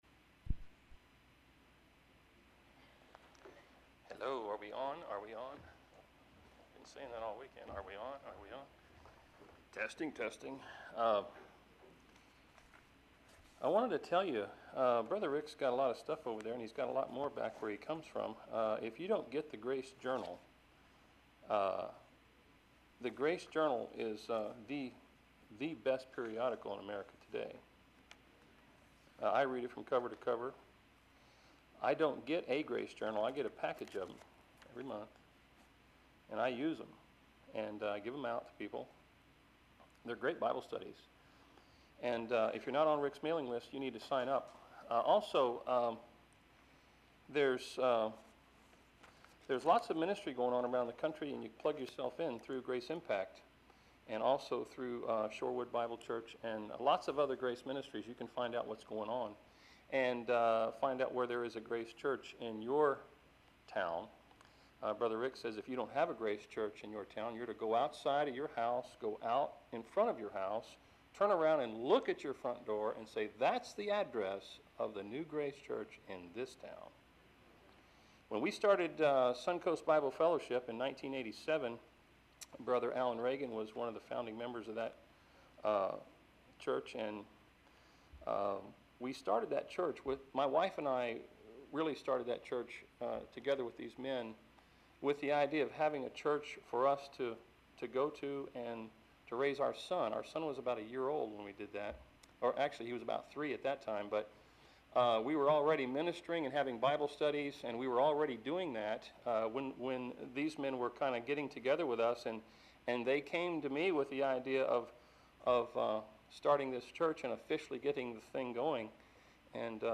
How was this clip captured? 2012 Florida Regional Grace Conference January 6-8 Plant City, FL